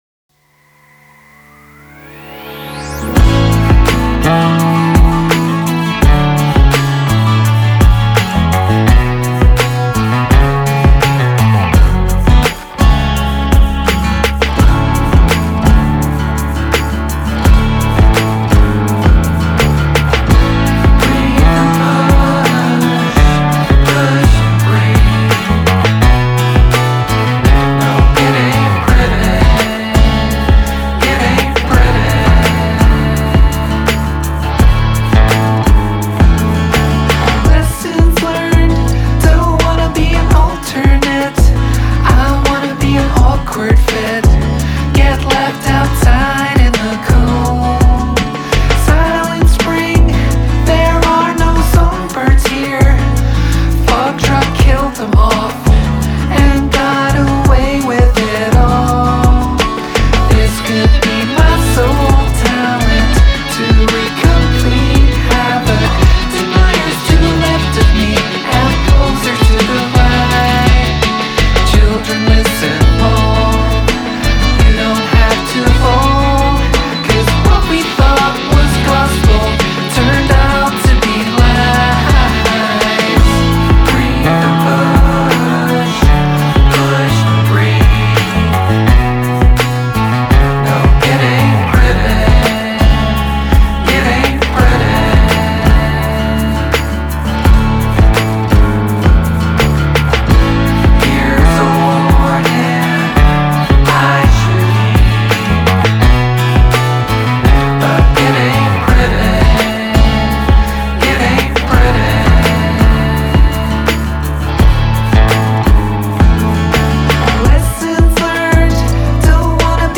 a major
daec#ae